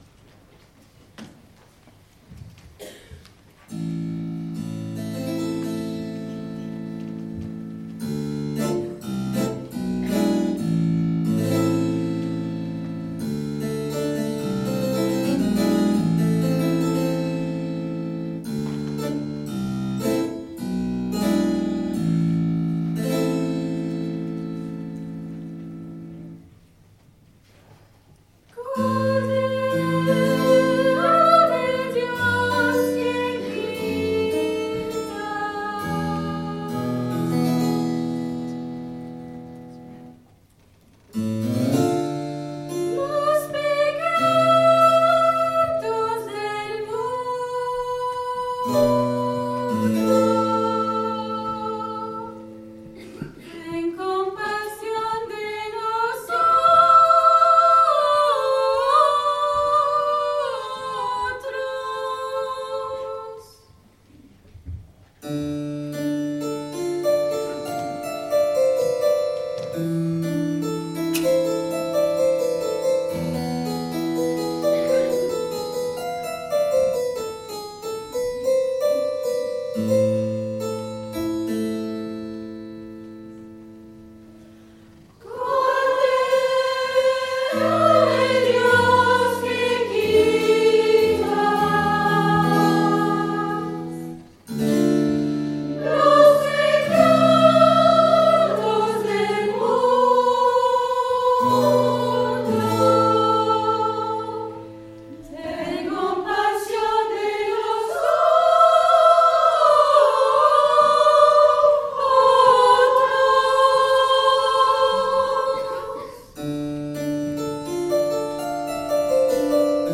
Enregistrements Audios du concert: